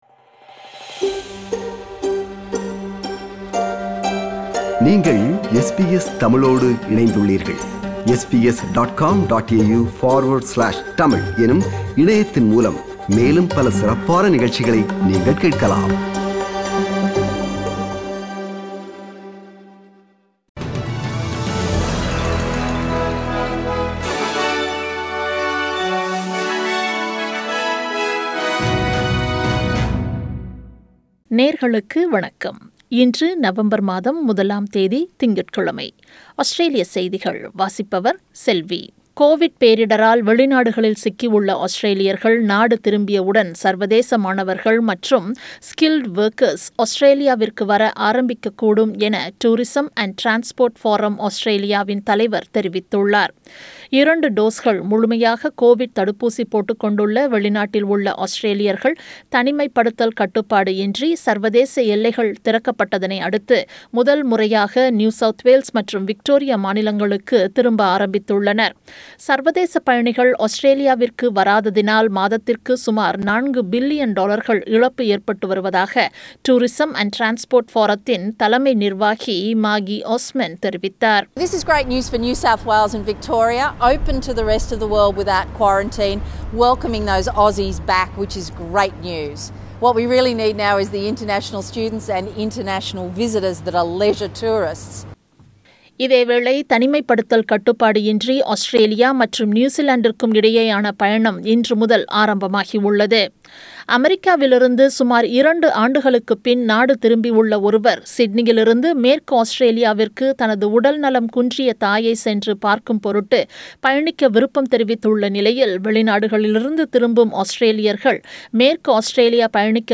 Australian News: 01 November 2021 – Monday